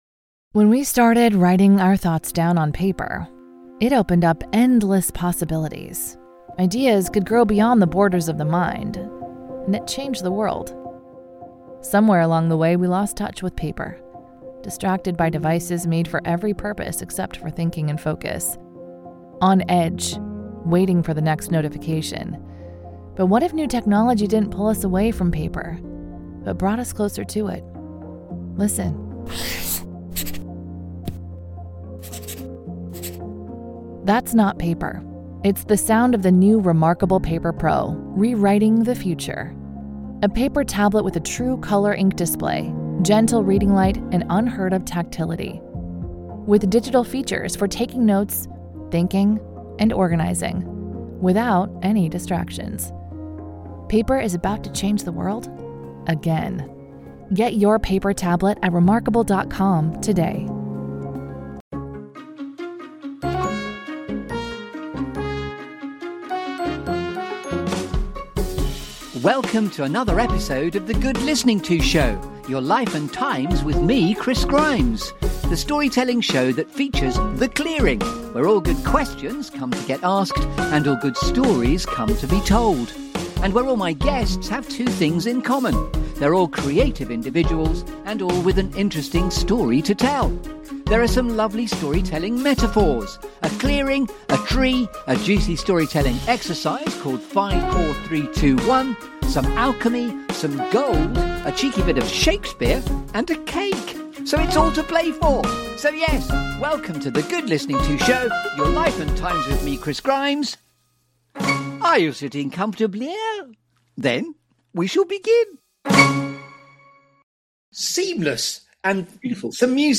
The Good Listening To Show is the Desert Island Discs of UKHR. This feel-good Storytelling Show that brings you ‘The Clearing’.